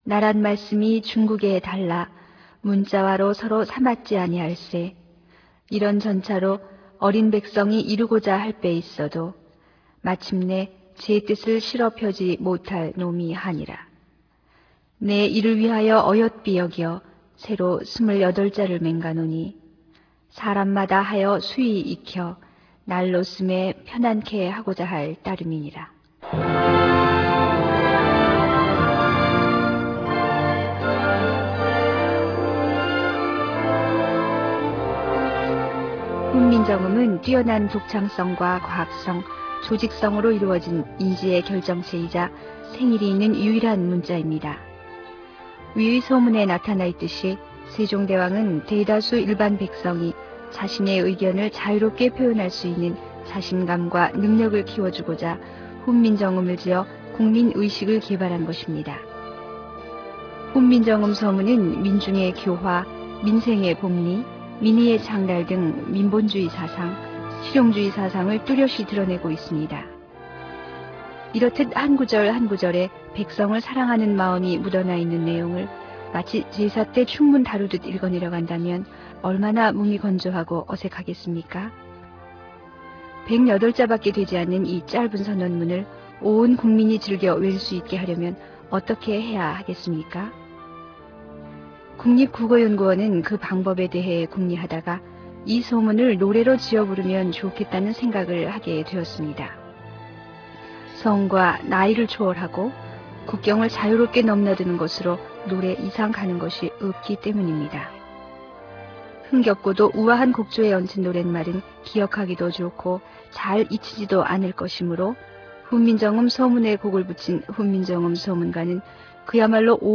낭독